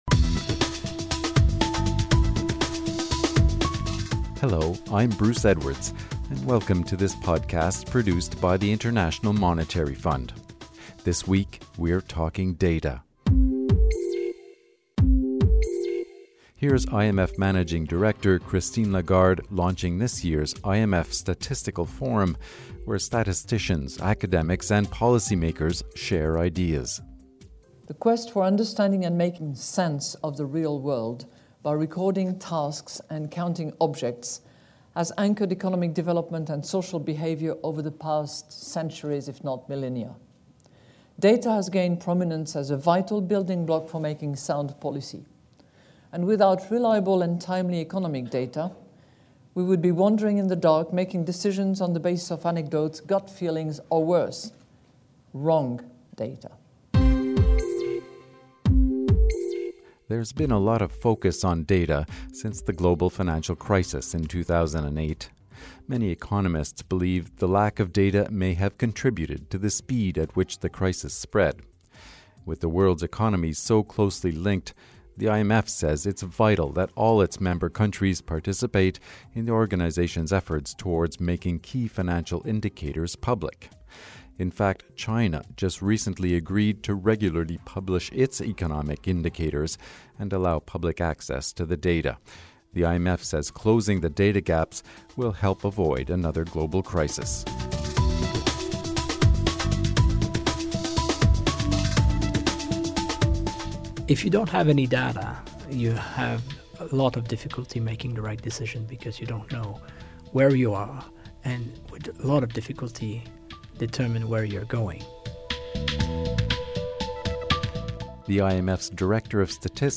Christine Lagarde: Managing Director, IMF